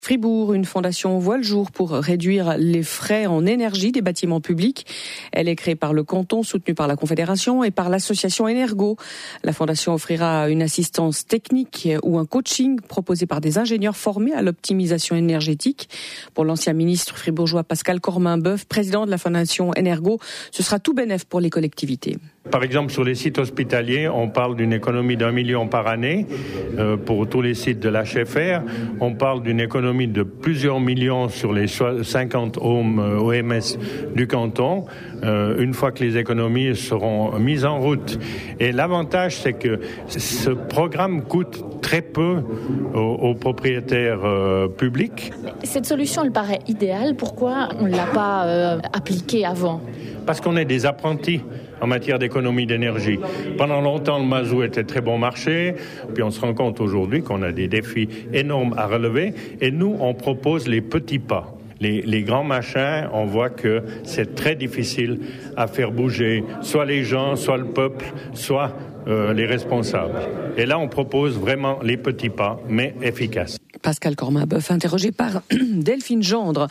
Fribourg_cree_une_fondation_pour_optimiser_lefficience_ener.mp3